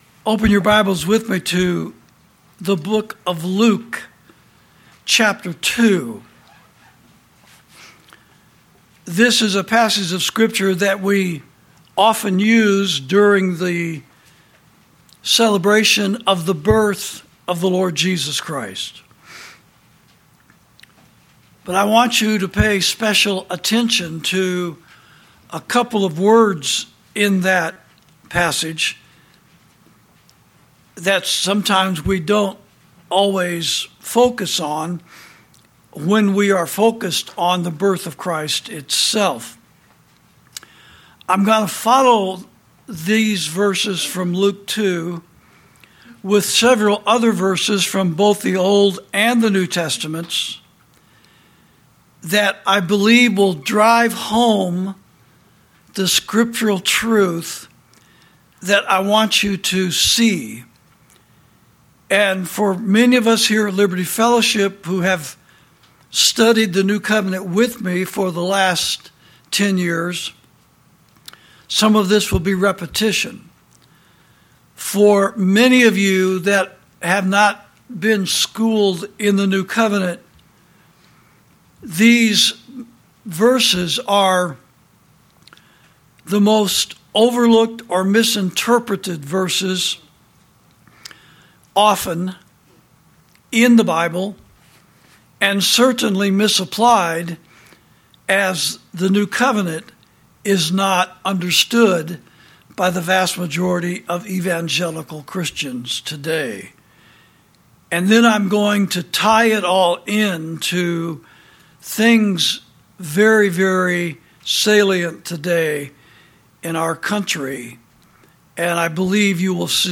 Sermons > God Is Not An American